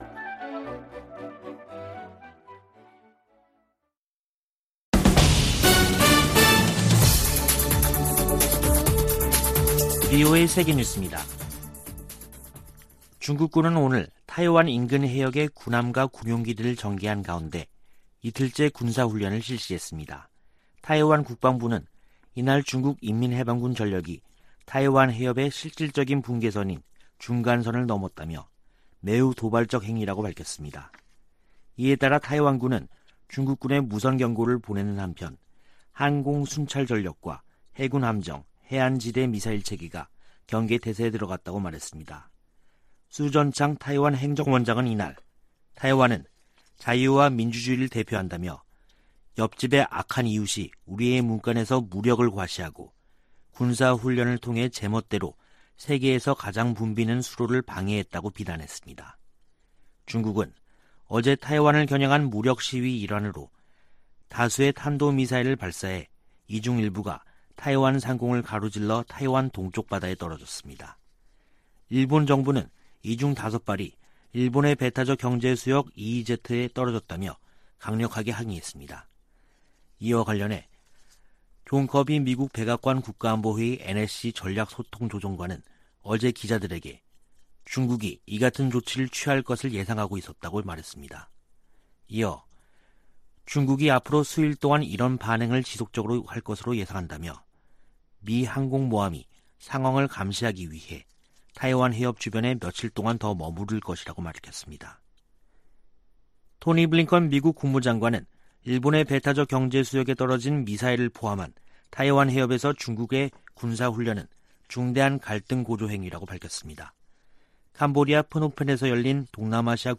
VOA 한국어 간판 뉴스 프로그램 '뉴스 투데이', 2022년 8월 5일 3부 방송입니다. 토니 블링컨 미 국무장관은 타이완 해협에서 무력으로 변화를 노리는 어떠한 시도에도 반대한다고 말했습니다. 타이완 정부는 미 하원의장의 방문을 중국에 대한 내정간섭이라고 북한이 주장한 데 대해, 타이완 주권 폄훼라고 비난했습니다. 필립 골드버그 신임 주한 미국대사는 한반도 비핵화가 미국의 목표라며 북한과 조건없는 대화에 열려 있다고 밝혔습니다.